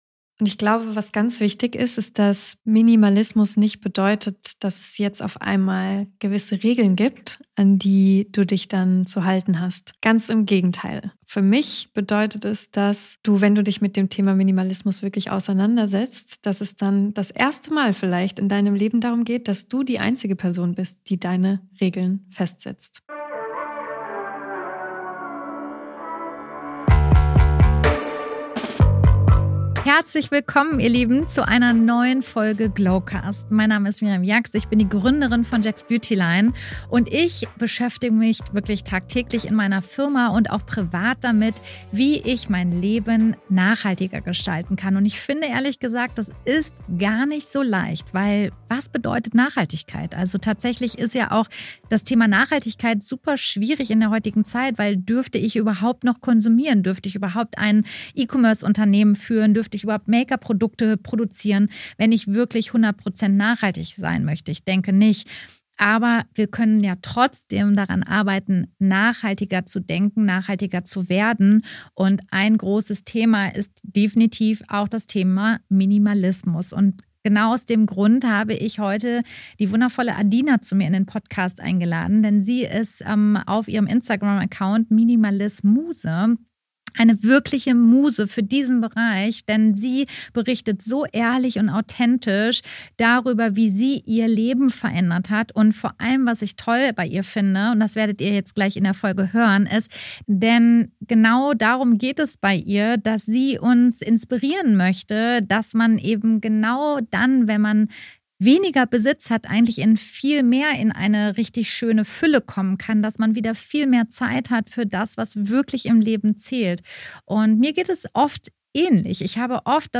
Für mich war dieses Gespräch ein Weckruf. Denn wie oft stehen wir vor übervollen Kleiderschränken und finden trotzdem nichts zum Anziehen?